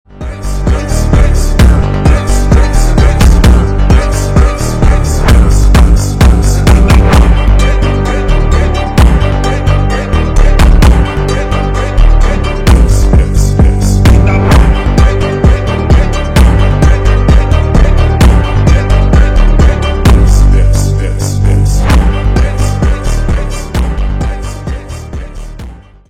зарубежные клубные тикток